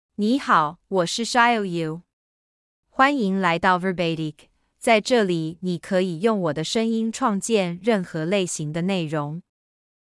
HsiaoYu — Female Chinese (Taiwanese Mandarin, Traditional) AI Voice | TTS, Voice Cloning & Video | Verbatik AI
HsiaoYu is a female AI voice for Chinese (Taiwanese Mandarin, Traditional).
Voice sample
Listen to HsiaoYu's female Chinese voice.
Female
HsiaoYu delivers clear pronunciation with authentic Taiwanese Mandarin, Traditional Chinese intonation, making your content sound professionally produced.